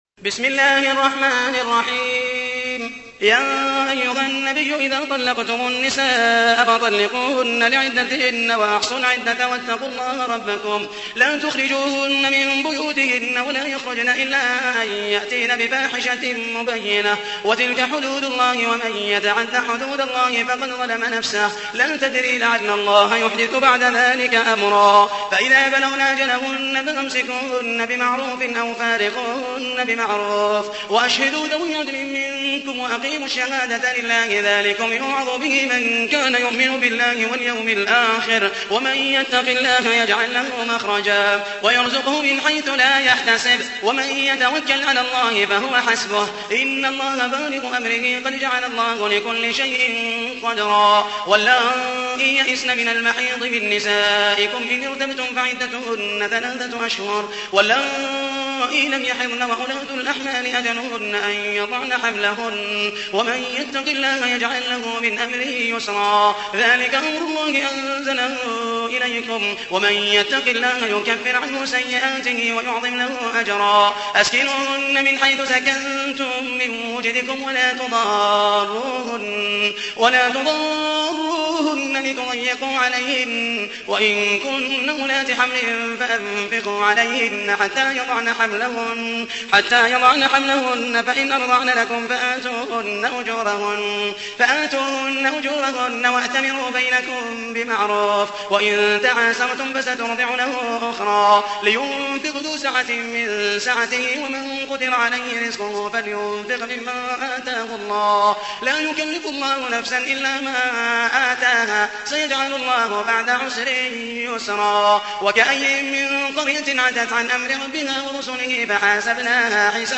تحميل : 65. سورة الطلاق / القارئ محمد المحيسني / القرآن الكريم / موقع يا حسين